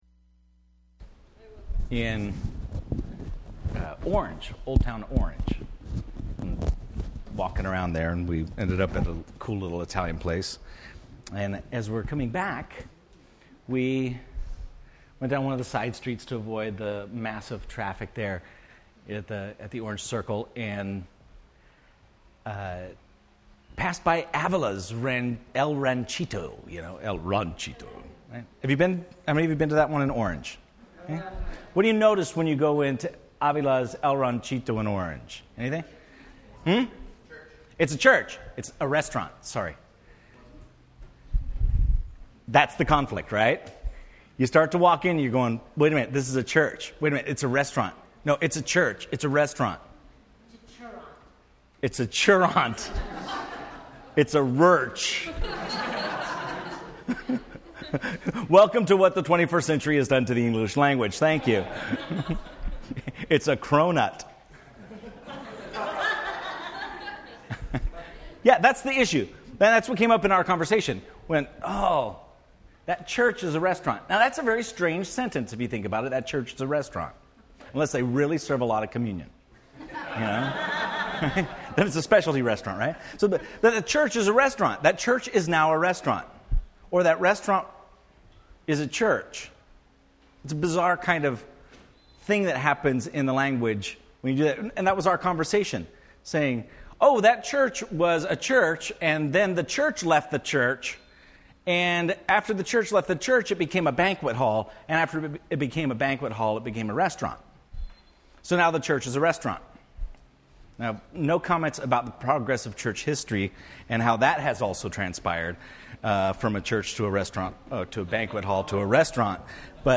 Metaphors of the Church Service Type: Sunday Morning %todo_render% Related « Metaphors of the Church